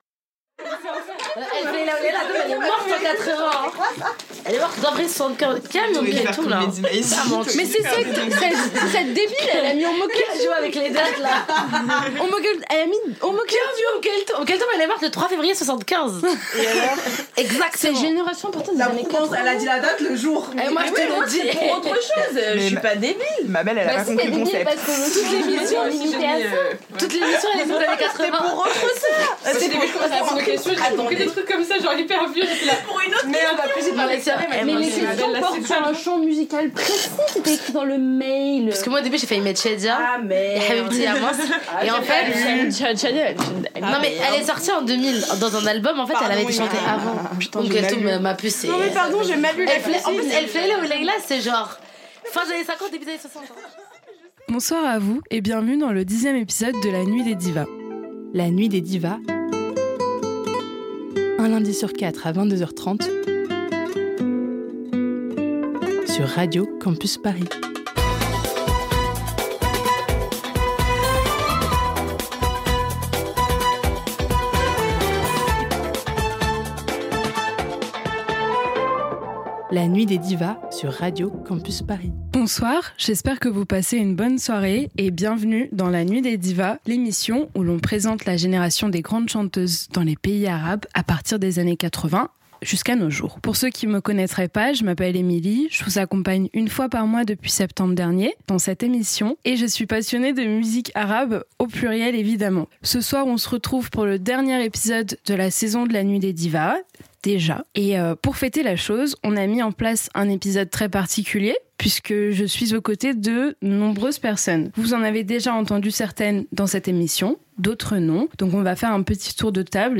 Pour célébrer la fin de cette aventure, on a invité six merveilleuses femmes afin de participer à des quizz musicaux sur des anecdotes à propos des artistes du champ musical de l'émission puis mettre en avant les morceaux préférés de chacune à travers des jeux! Retrouvez cet épisode de fête, qui a été un plaisir à enregistrer, ponctué d'anecdotes, de blagues et de rires qui pourront en amuser plus d'un ,dès